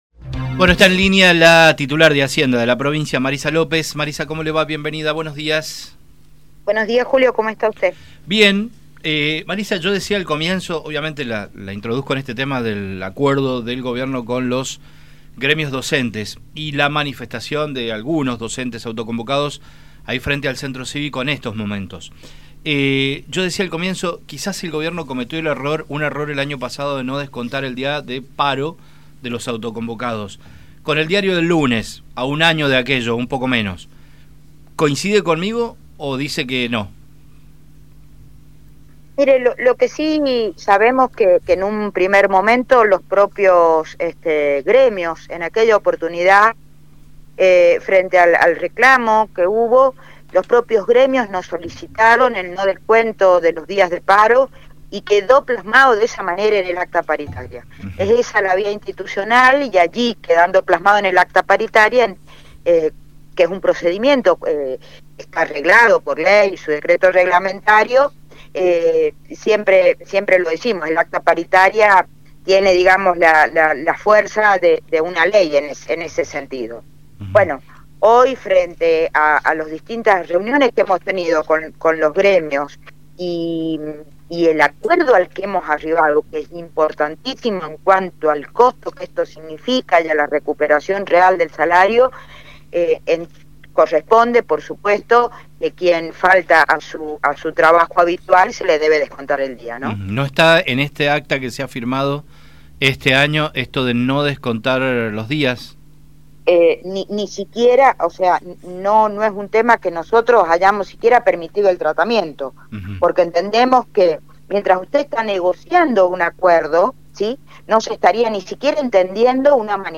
La Ministra de Hacienda, Marisa López estuvo en los micrófonos de Radio Sarmiento para hablar acerca del incremento salarial a los estatales.